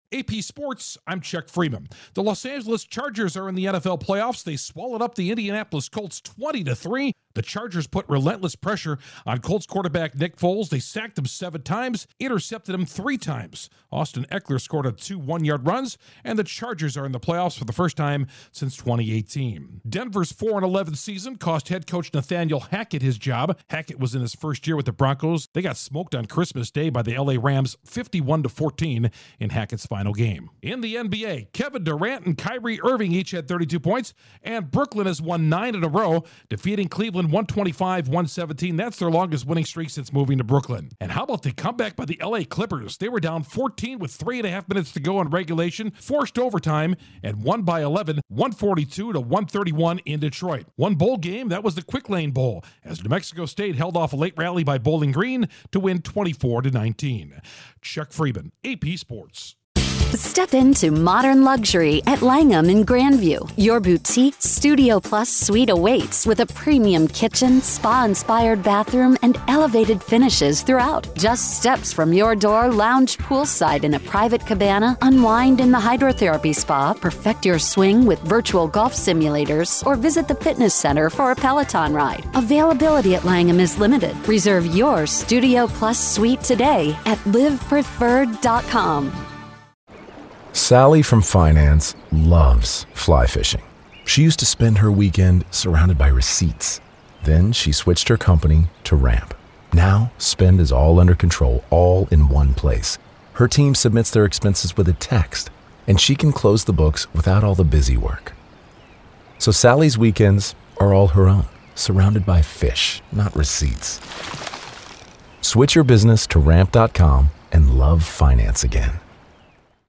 The Los Angeles Charges make the Playoffs, the Broncos fire their coach and a the Clippers bounce back. Correspondent